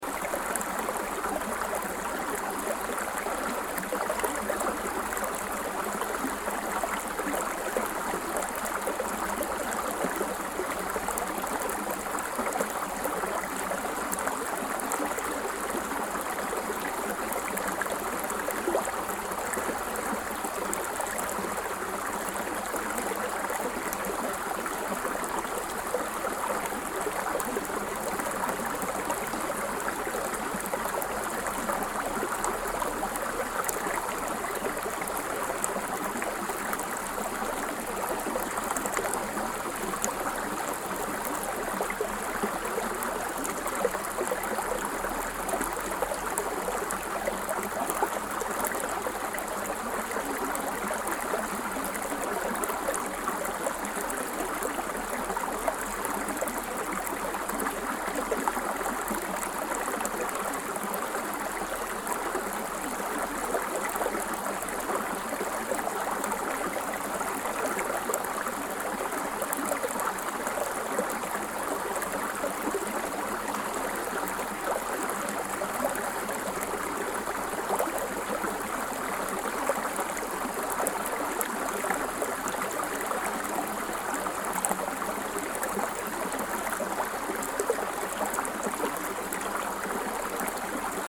3D Ambisonic Babbling Brook Nature Sound Effect Loop
Description: 3D ambisonic babbling brook nature sound effect loop.
Capture the calming and natural atmosphere of a peaceful stream to enhance any audio-visual production.
3d-ambisonic-babbling-brook-nature-sound-effect-loop.mp3